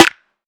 SNARE.109.NEPT.wav